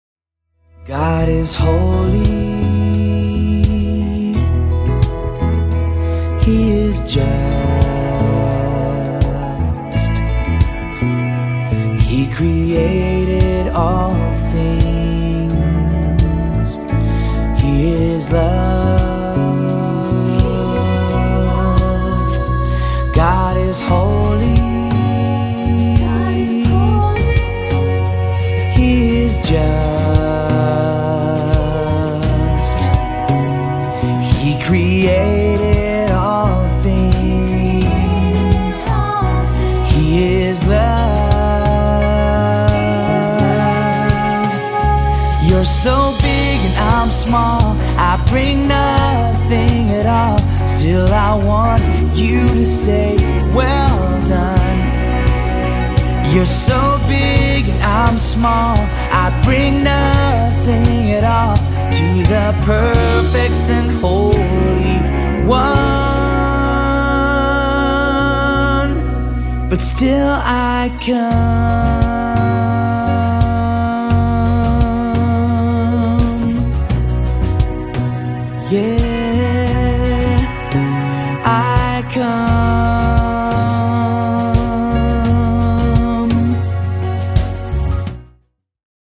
energetic praise and worship music